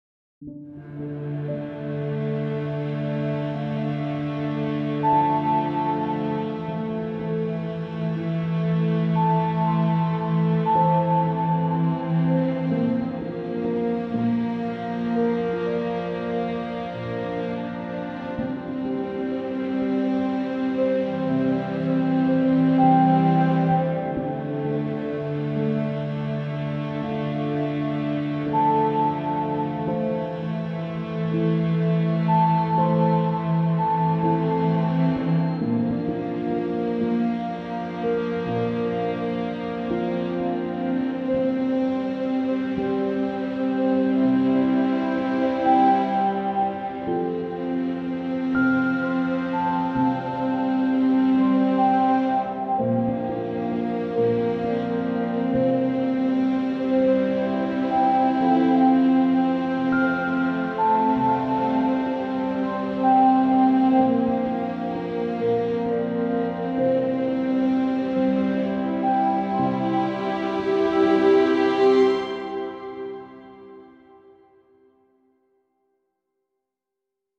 Minimal